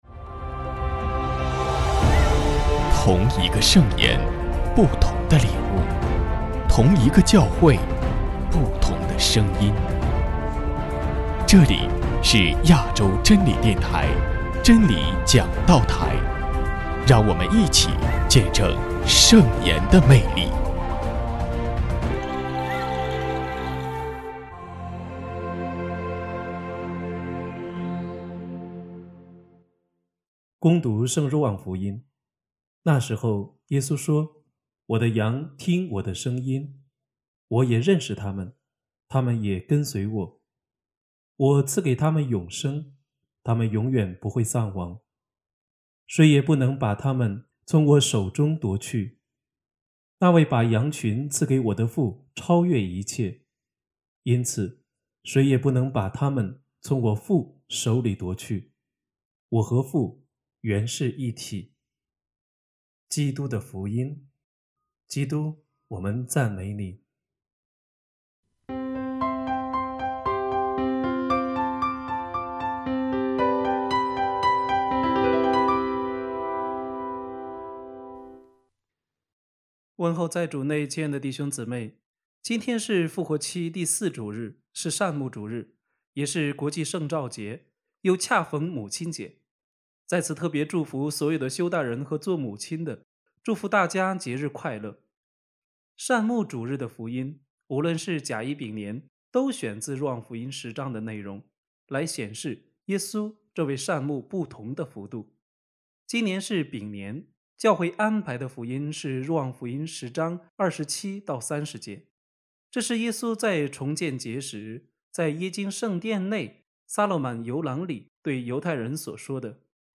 【真理讲道台 】71|复活节第四主日证道——耶稣善牧